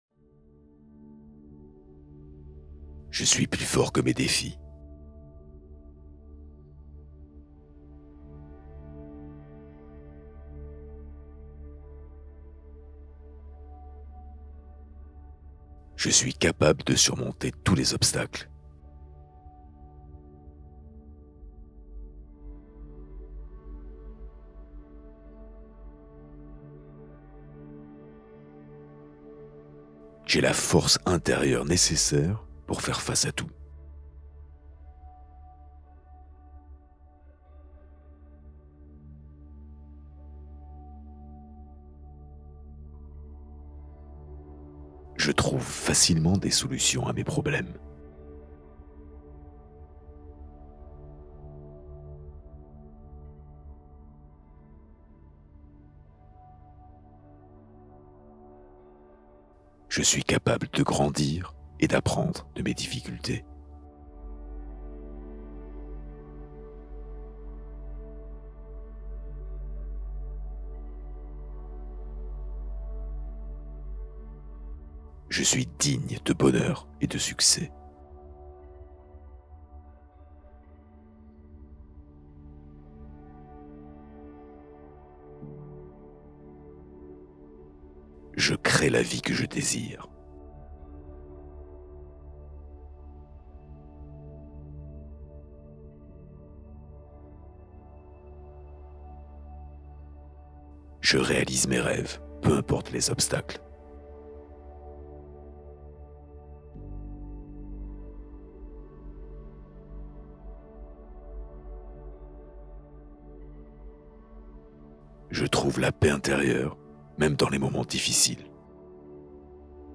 Hypnose puissante : traverser les moments difficiles